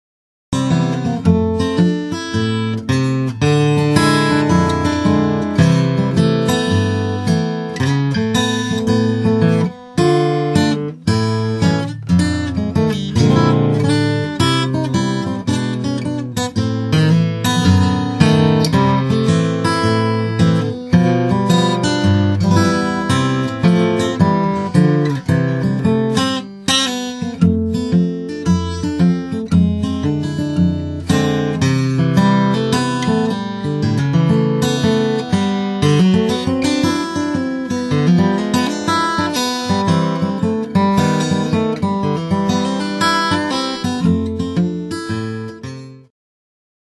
captures the time period between Ragtime and Jazz